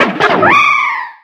Cri de Darumarond dans Pokémon X et Y.